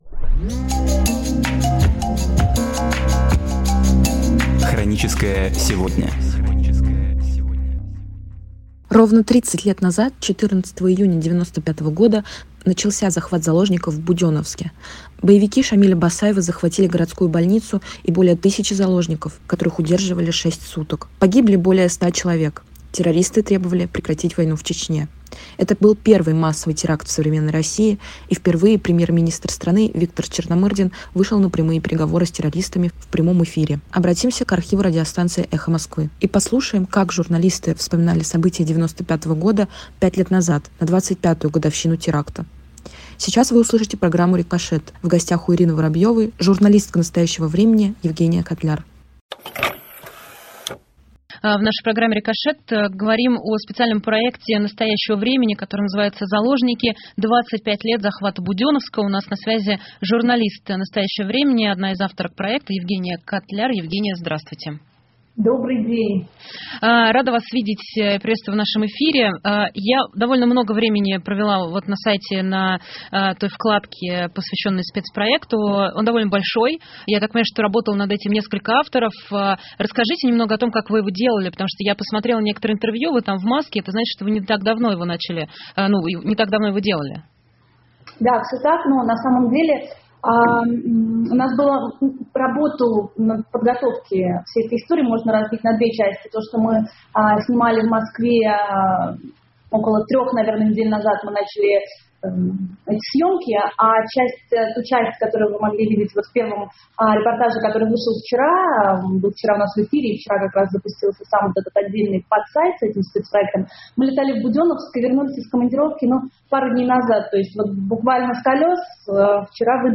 Программы из архива «Эха Москвы»
Обратимся к архиву радиостанции «Эхо Москвы» и послушаем, как журналисты вспоминали события 1995 года 5 лет назад — на 25 годовщину теракта.